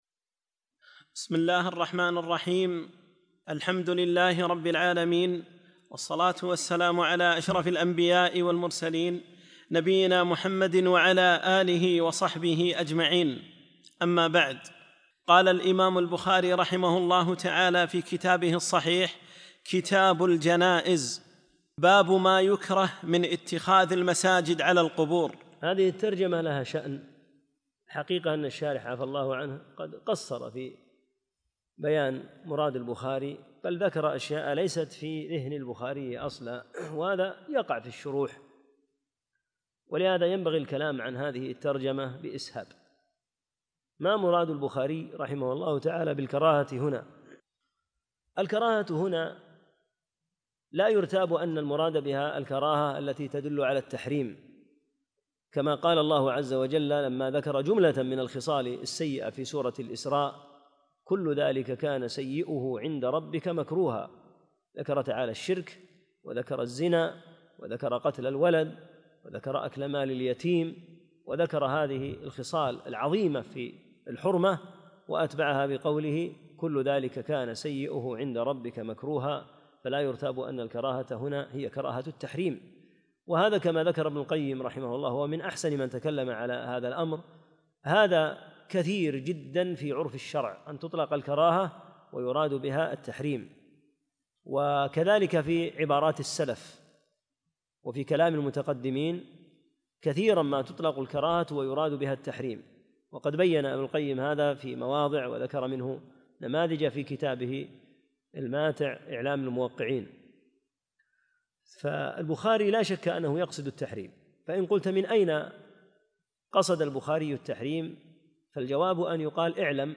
9- الدرس التاسع